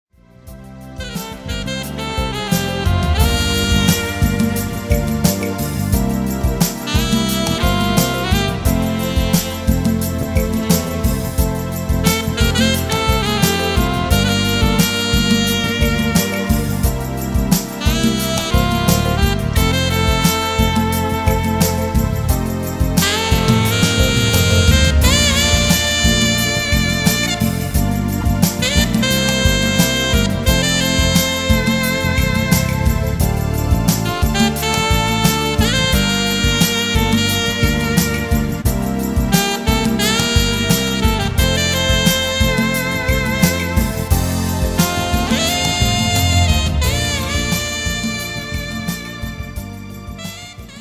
(SOPRANO, ALTO & TENOR SAXES)
(BASS)
(ACUSTIC & ELECTRIC GUITARS)
DRUM& PERCUSSION PROGRAMMING)